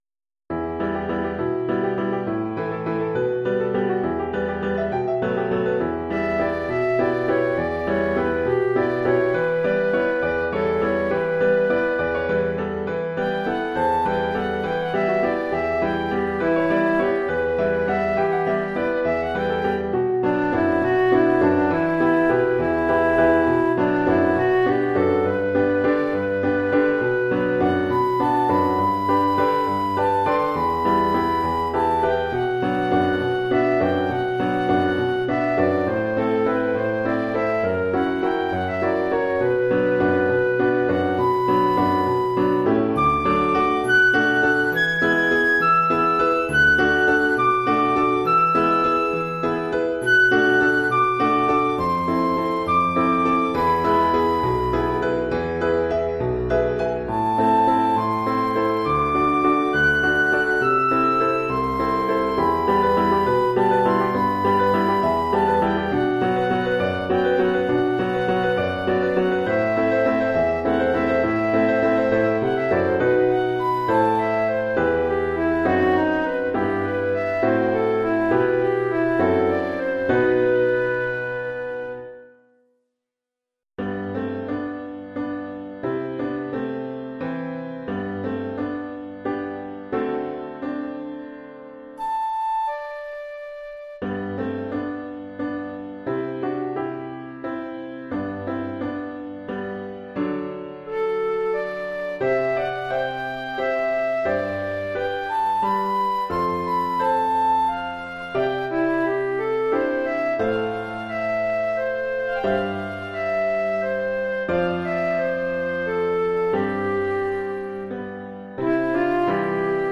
Formule instrumentale : Flûte et piano
Oeuvre pour flûte et piano.